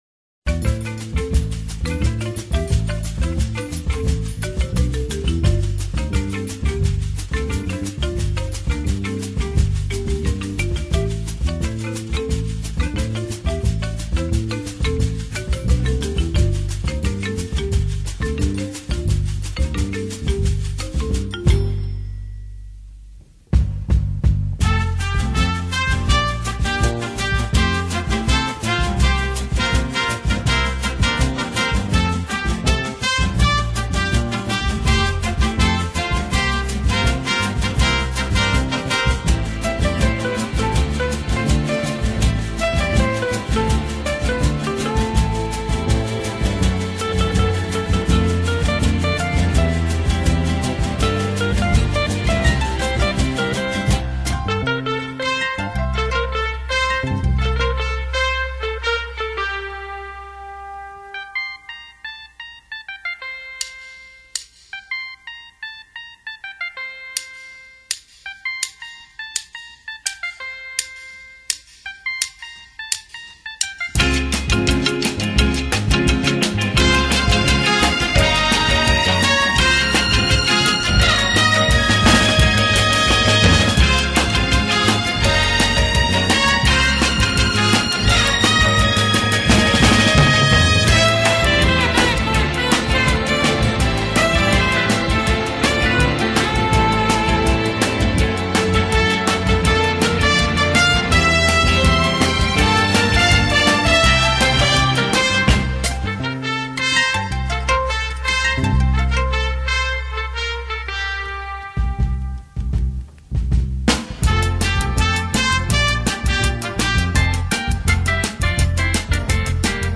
Instrumentais Para Ouvir: Clik na Musica.